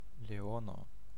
Ääntäminen
Synonyymit roi des animaux héros Lion bestion Ääntäminen France: IPA: [ljɔ̃] Haettu sana löytyi näillä lähdekielillä: ranska Käännös Konteksti Ääninäyte Substantiivit 1. leono eläintiede Suku: m .